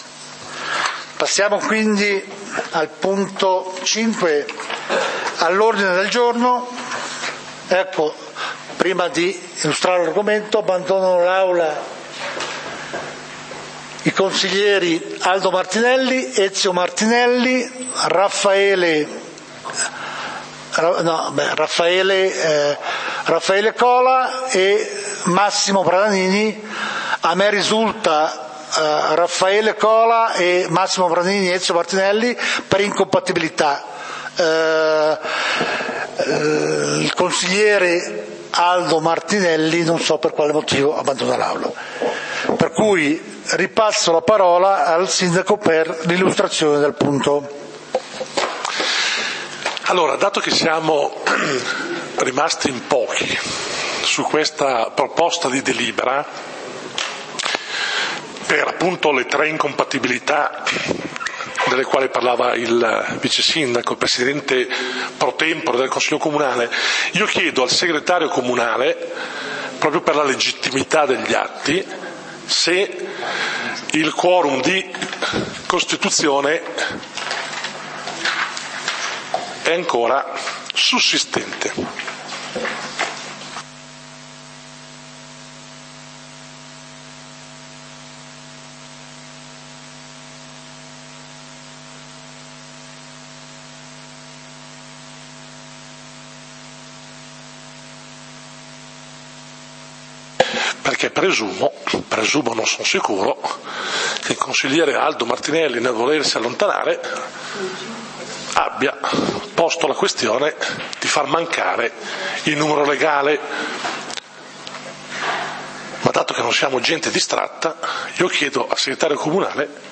Consiglio comunale di Valdidentro del 18 Dicembre 2014